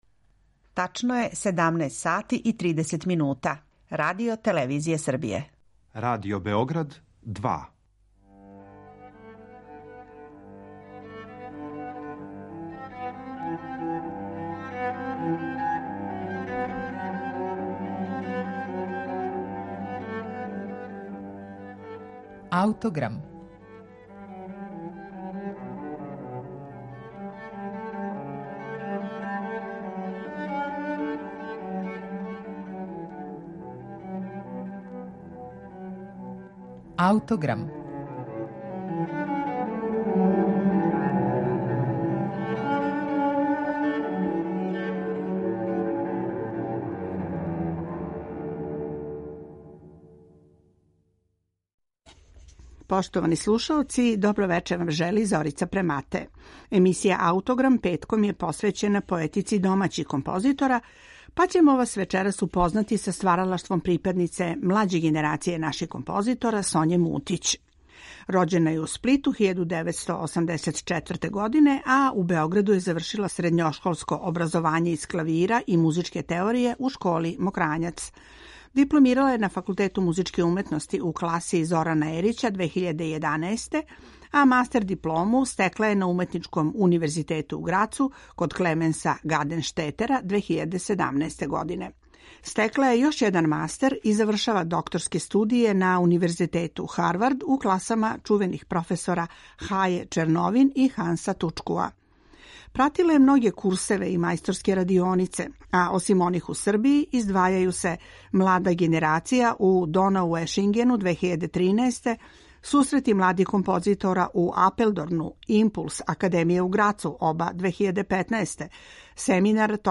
за гудачки трио и електронику